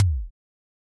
eleDrum02.wav